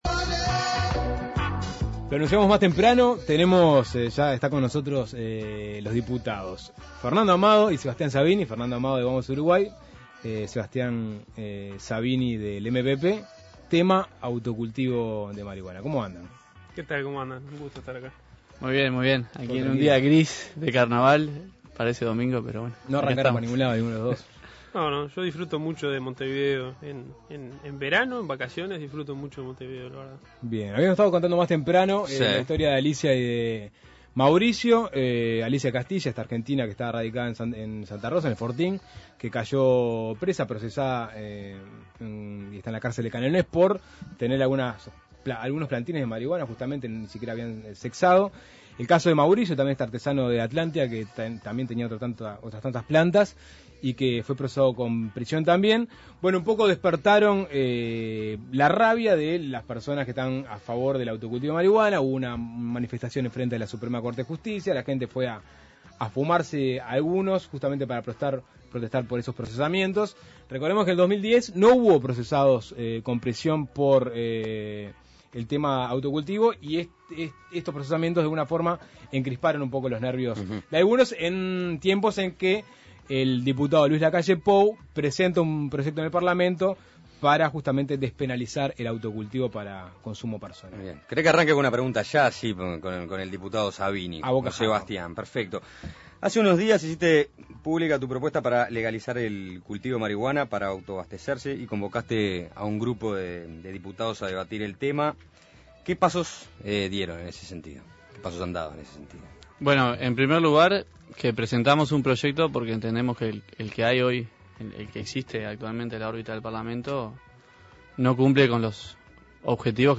Dos diputados analizan iniciativas pro-despenalización de marihuana